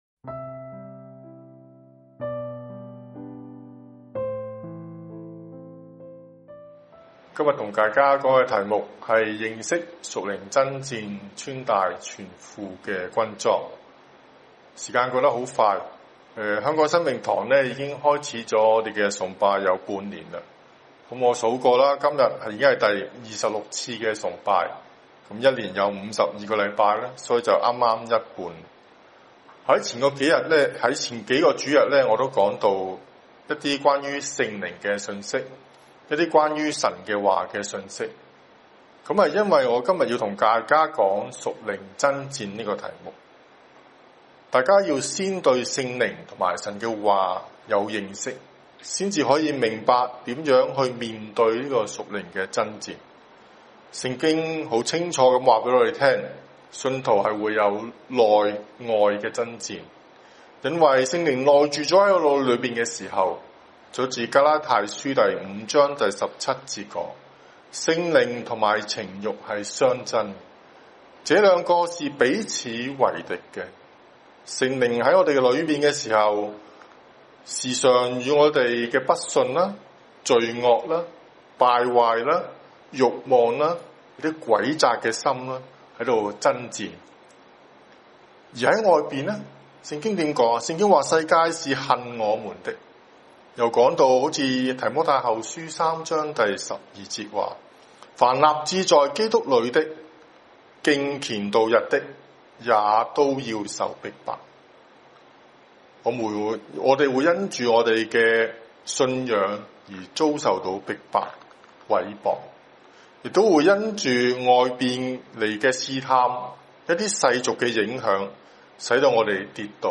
认识属灵争战，穿戴全副军装[9月6日崇拜]